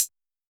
UHH_ElectroHatC_Hit-35.wav